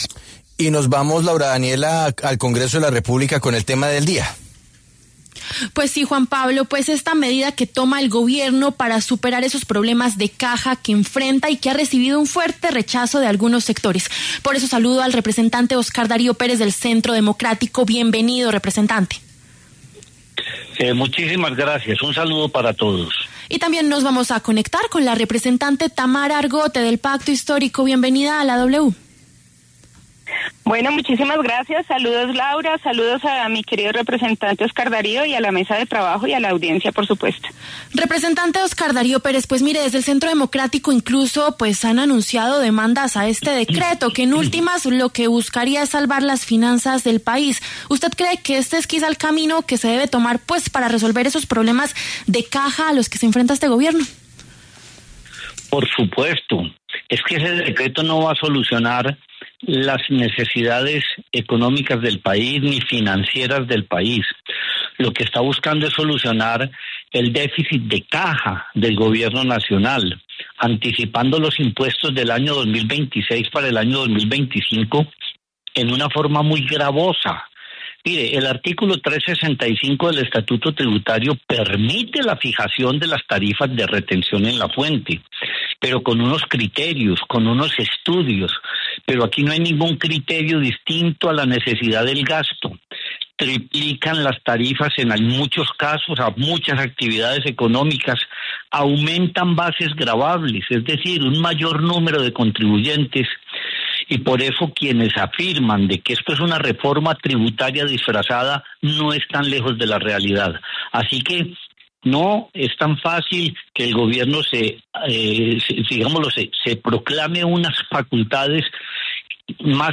Debate: decreto para cobrar impuestos de 2026 por anticipado enciende alarmas en el Congreso
Los representantes Óscar Darío Pérez, del Centro Democrático, y Tamara Argote, del Pacto Histórico, pasaron por los micrófonos de La W. Desde el partido uribista demandaron el decreto.